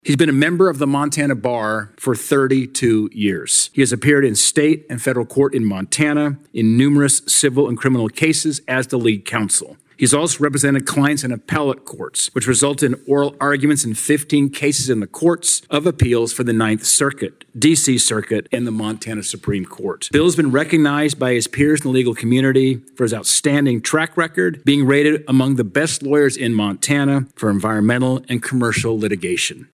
Senator Steve Daines made these remarks before the Senate Judiciary Committee today, introducing William Mercer as President Trump’s nominee to be the next United States District Judge for the District of Montana.